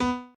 b_pianochord_v100l12o4b.ogg